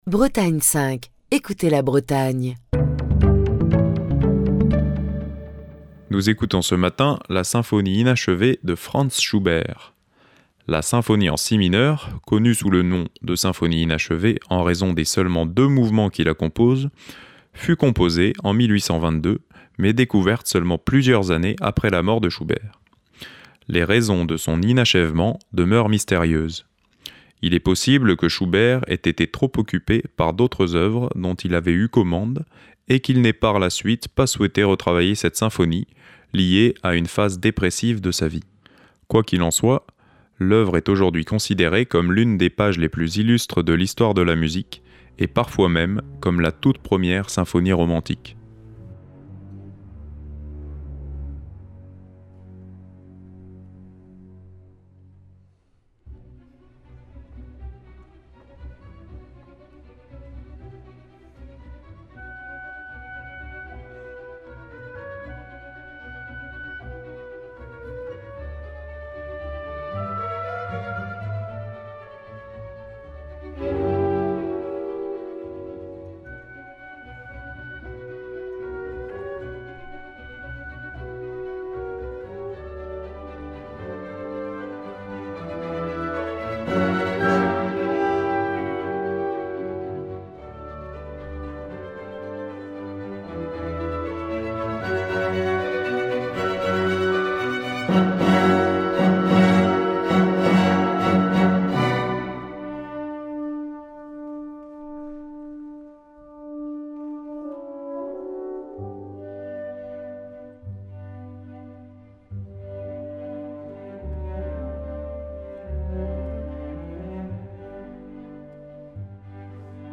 Les Dissonances L’orchestre "Les Dissonances" présente la Symphonie Inachevée de Schubert, sous la direction de... personne ! En effet, la particularité de cet orchestre est de jouer sans chef.